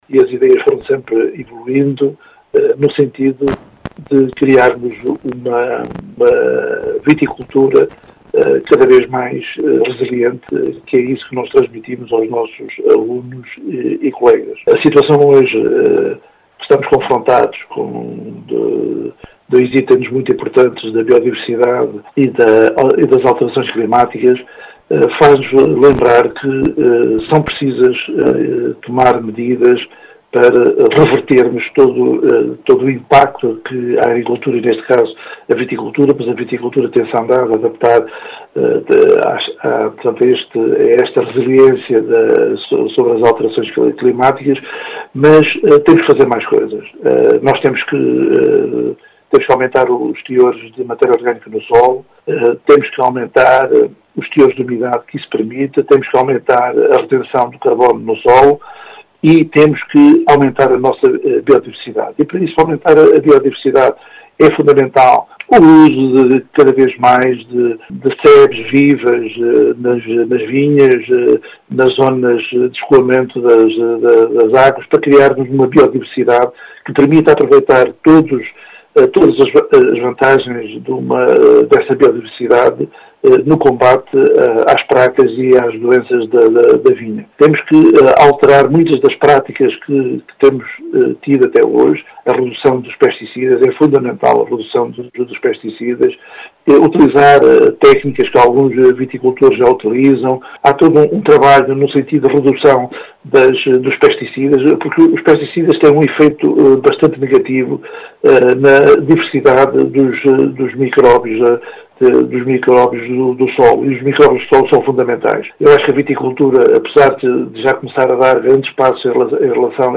Em declarações à Rádio Vidigueira